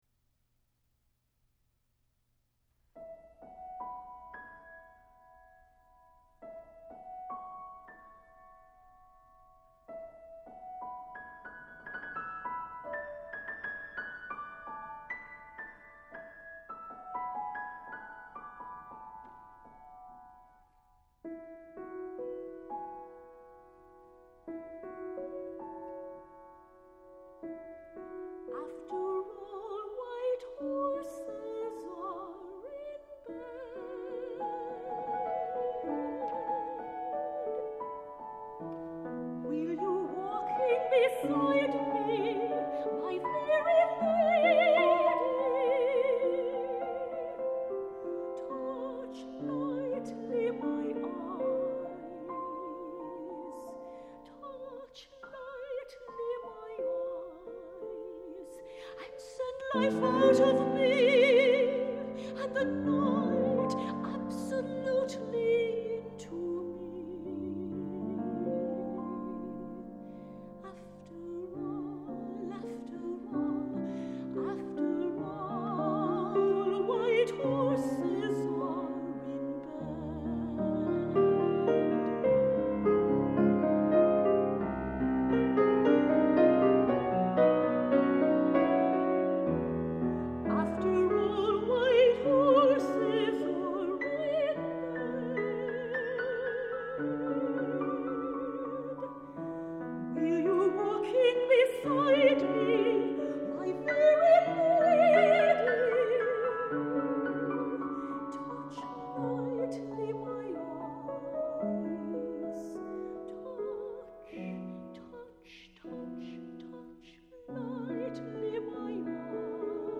for High Voice and Piano (2006)
soprano
piano.
The musical emphasis is on the vocal lines.